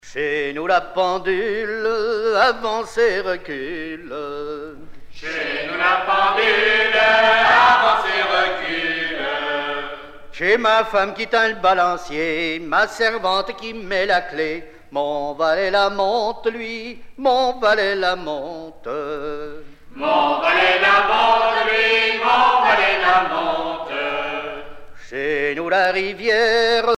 danse : ronde : grand'danse
Genre énumérative
Pièce musicale éditée